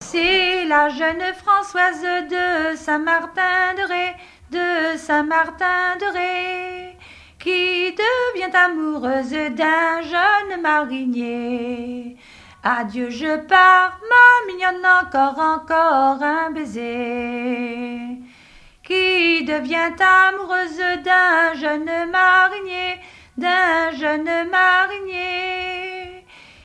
danse : ronde : rond de l'Île d'Yeu
Témoignages sur le mariage et chansons traditionnelles
Pièce musicale inédite